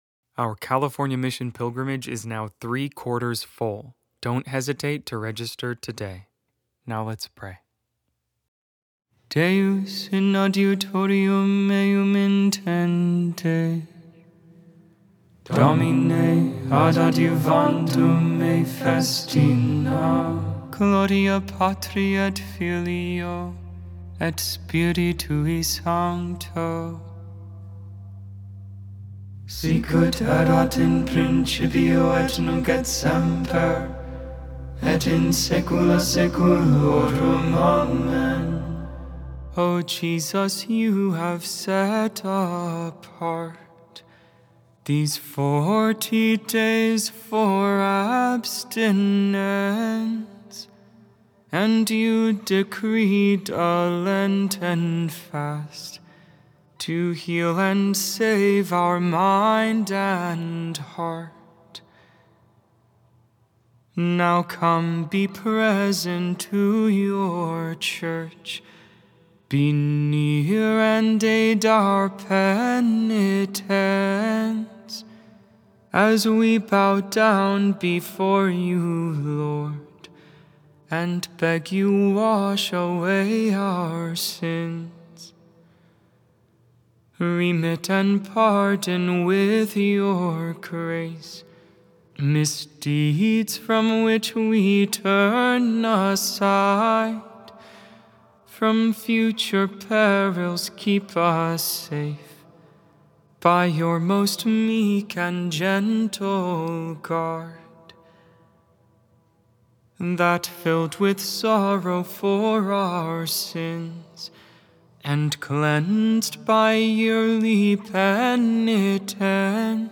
Vespers, Evening Prayer for the 1st Thursday of Lent, February 26, 2026.Made without AI. 100% human vocals, 100% real prayer.
A fully chanted Liturgy of the Hours experience.